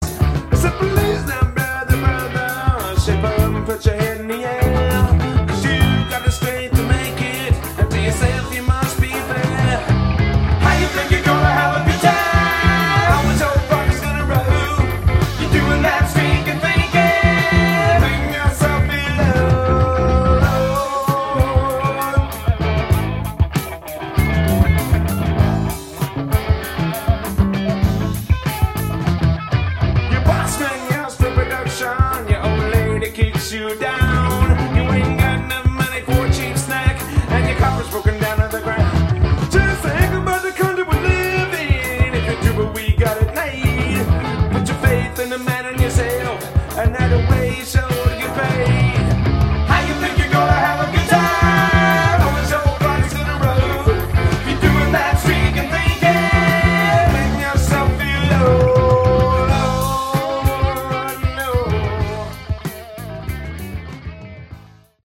Category: Pomp AOR